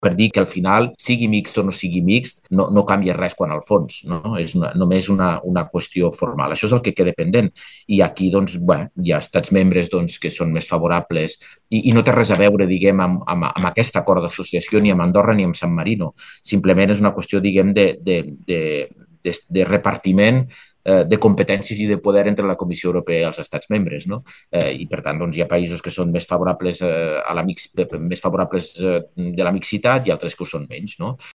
En una compareixença telemàtica amb els mitjans, Espot ha afirmat que l’únic punt pendent és determinar la naturalesa jurídica de l’acord, és a dir, si serà considerat un acord mixt o no.